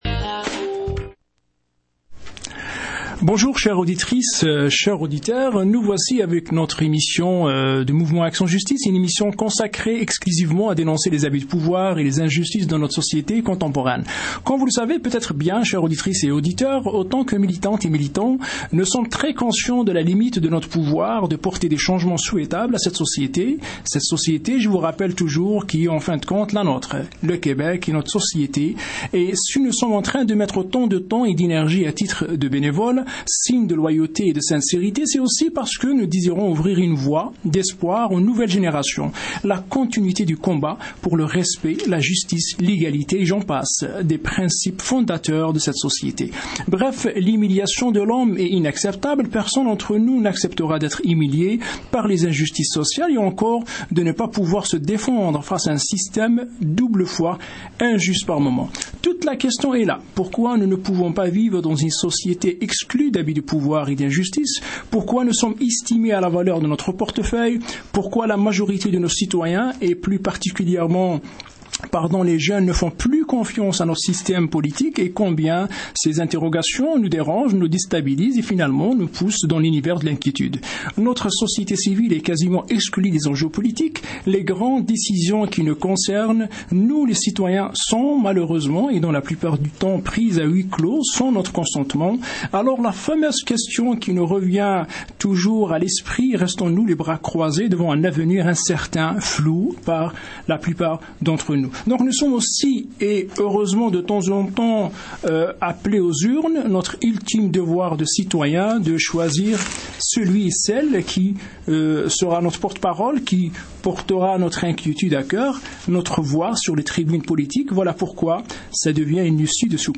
Entrevue réalisée dans le cadre de notre émission sur les ondes de Radio Centre-ville :